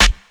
• 2000s Loud Acoustic Snare Sound E Key 63.wav
Royality free acoustic snare sample tuned to the E note. Loudest frequency: 2324Hz